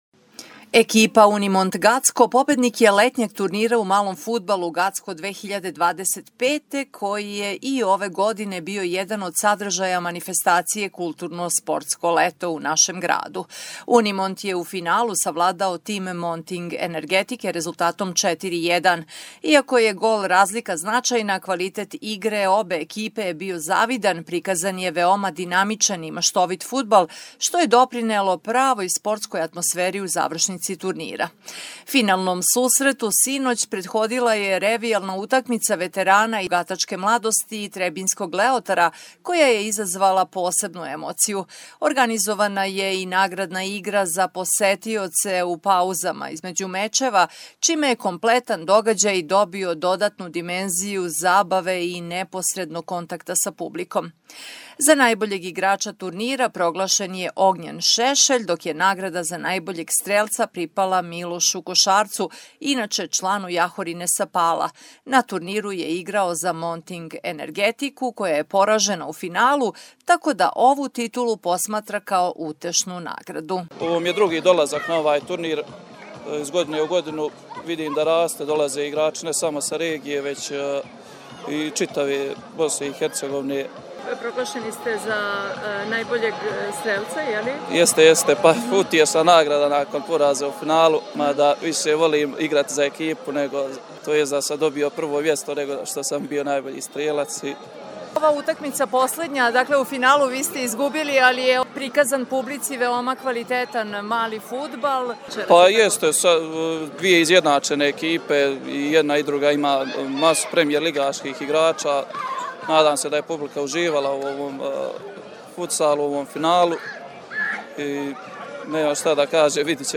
prilog-sa-turnira-malog-fudbala.mp3